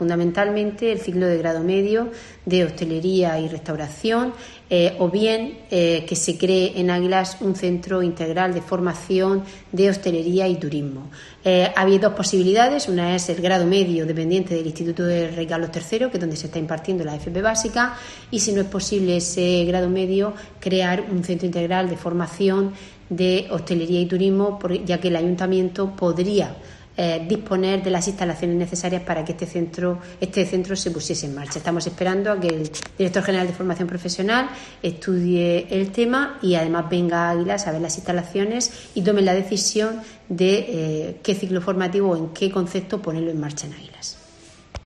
María del Carmen Moreno, alcaldesa de Águilas